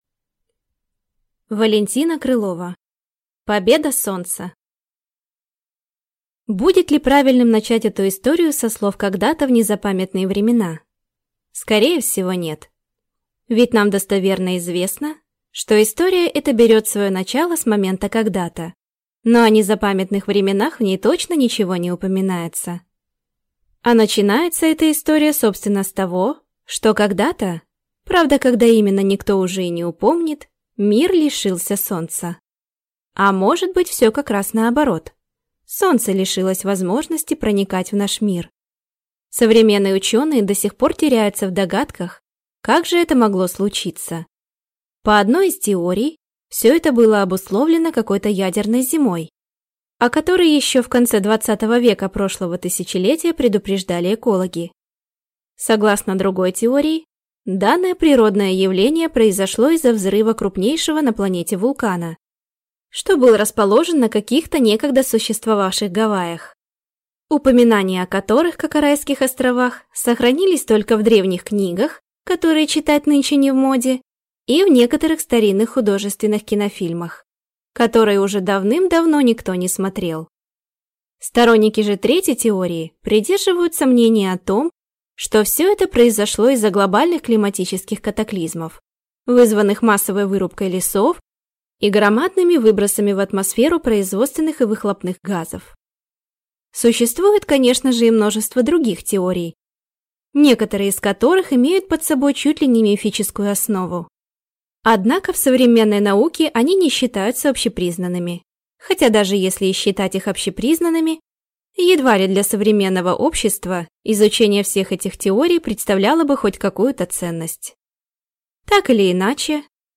Аудиокнига Победа Солнца | Библиотека аудиокниг